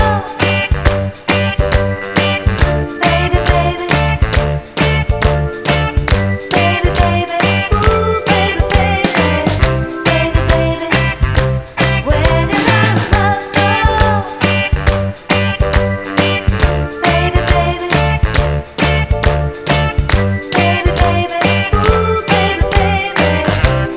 With Chord Soul / Motown 2:25 Buy £1.50